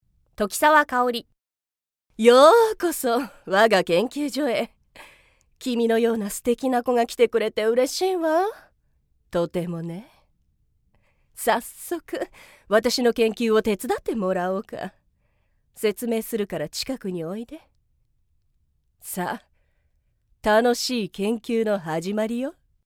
◆年上お姉さん◆